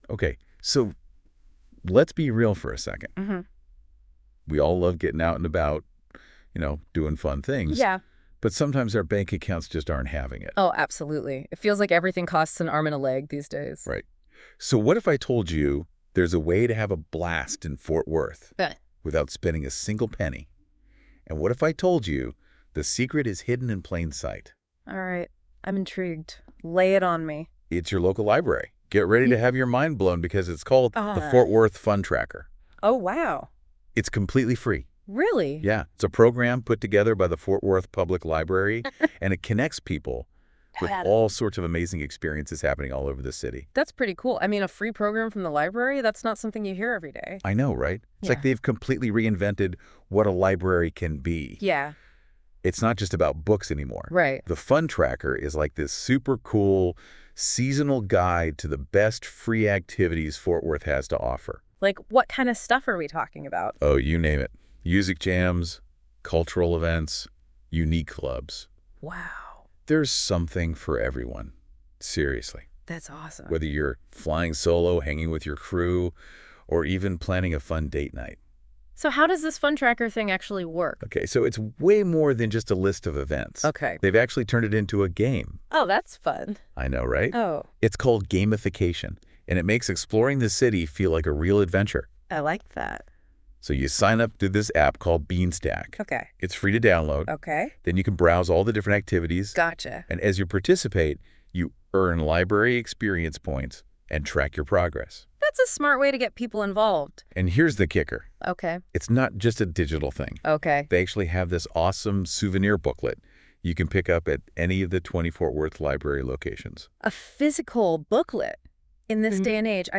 *audio generated with NotebookLM